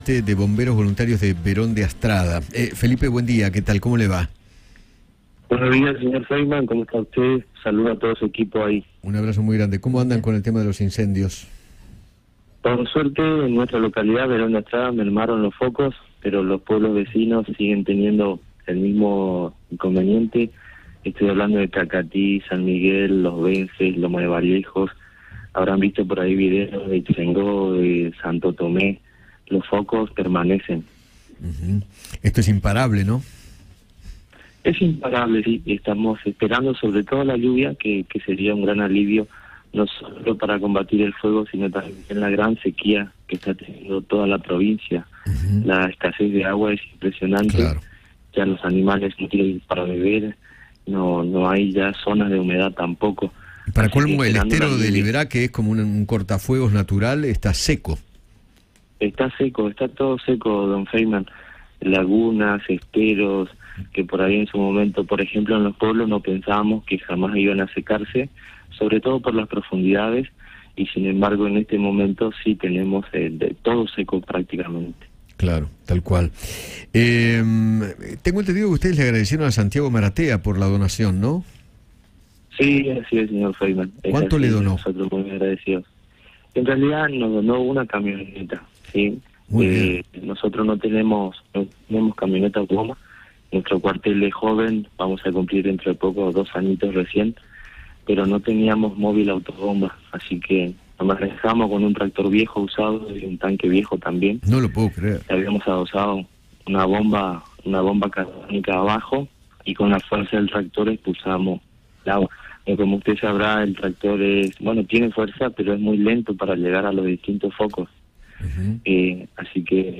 dialogó con Eduardo Feinmann sobre los incendios en Corrientes y habló del emotivo video que grabó junto con sus compañeros en agradecimiento al influencer Santiago Maratea por la colecta.